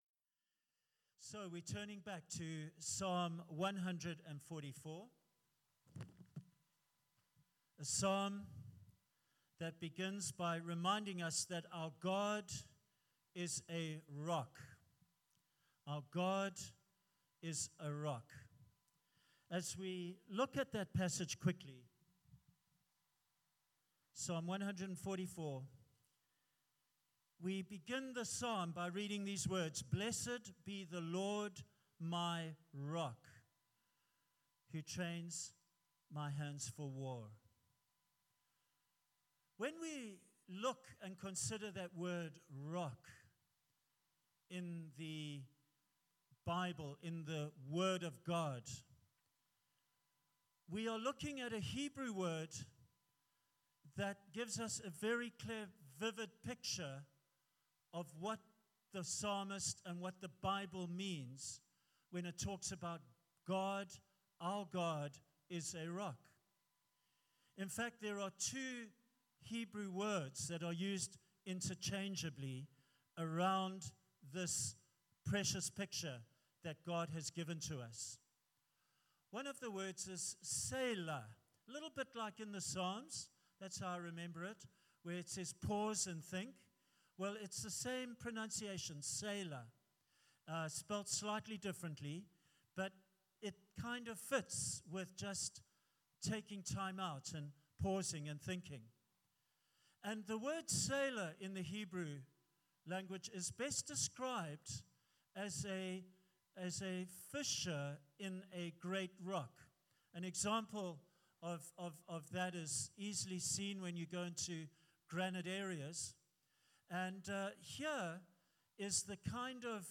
The reading is Psalm chapter 144.